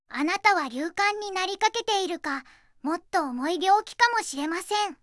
voicevox-voice-corpus / ita-corpus /ずんだもん_ノーマル /EMOTION100_030.wav